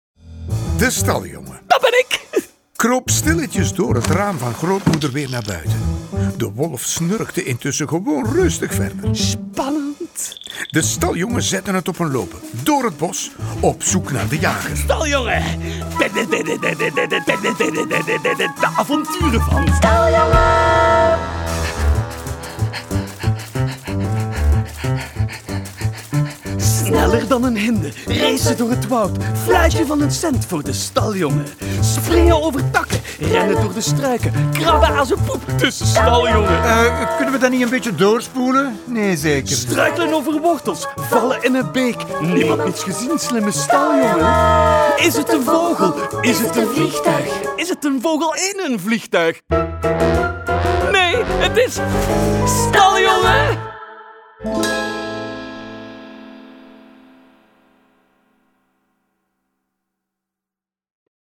Door de Vlaamse stemmen staat hdt verhaal dicht bij de luisteraar.
Een sprookje met soundeffecten, grappig voor kindjes en ouders.